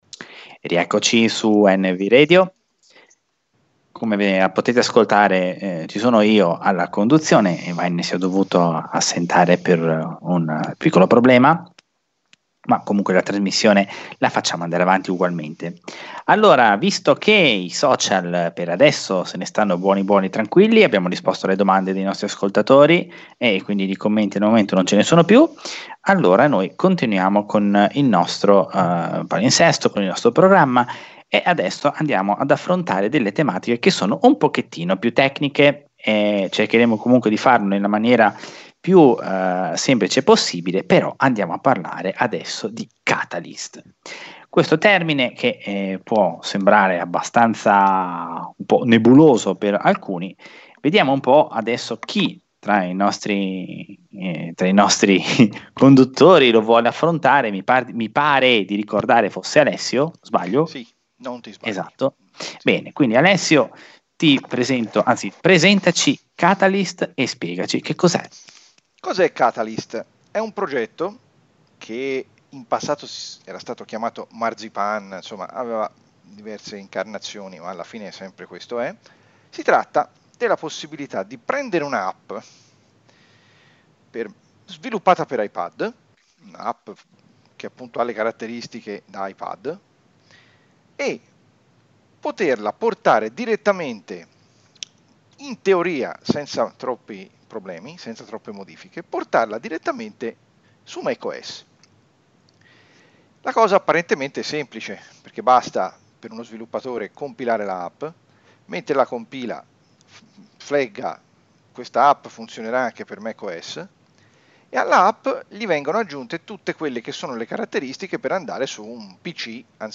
Diretta NvRadio: Alla scoperta di Catalina, seconda parte